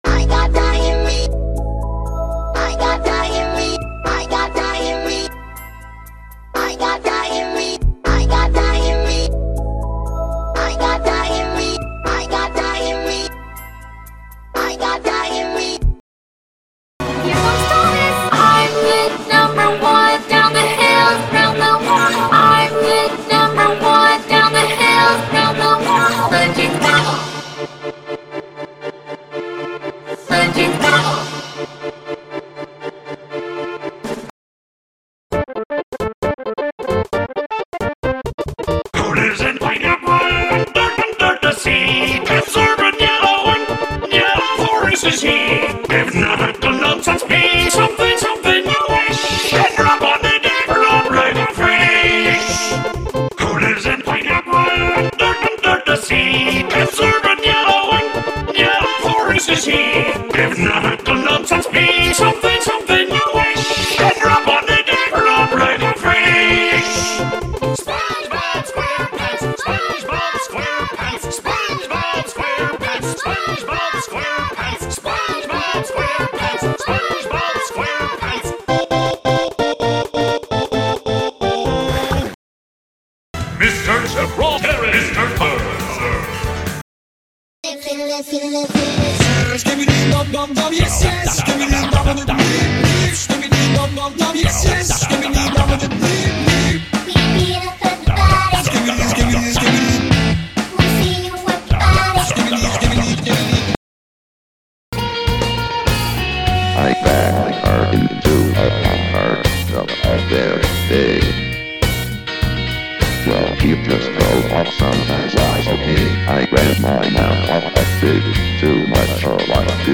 Music / Game Music
mashup